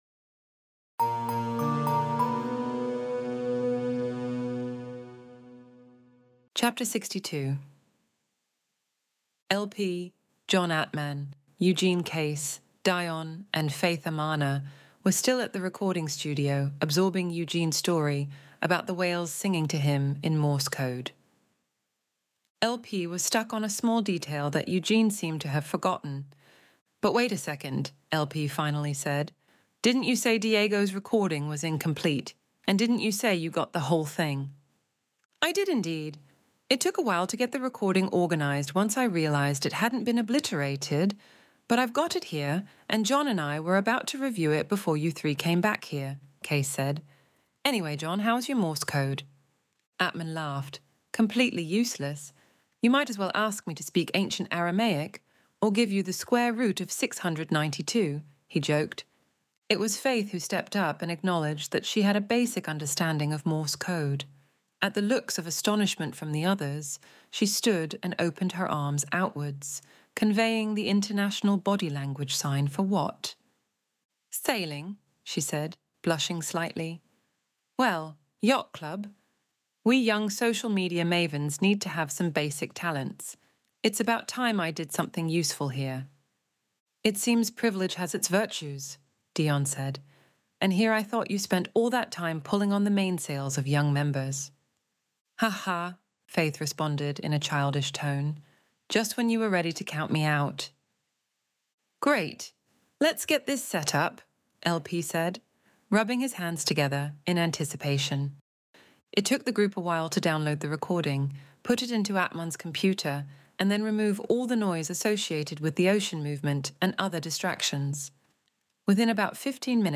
Extinction Event Audiobook Chapter 62